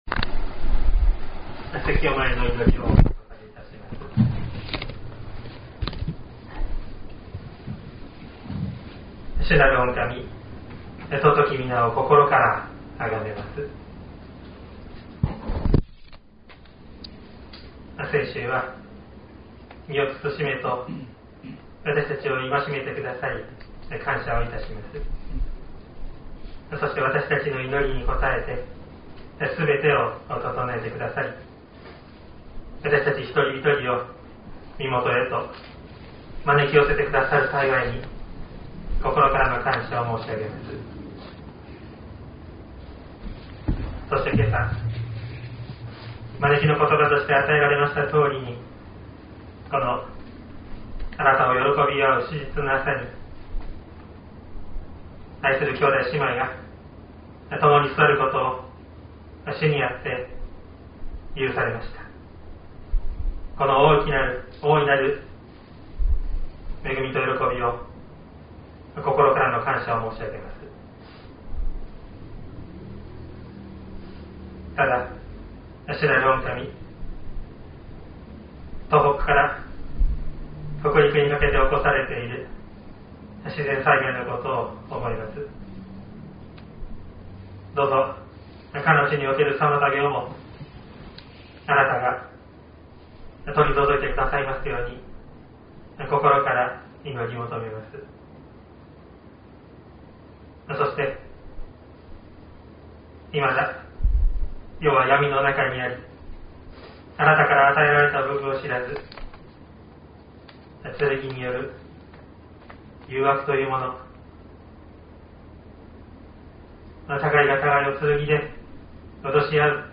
説教アーカイブ。
音声ファイル 礼拝説教を録音した音声ファイルを公開しています。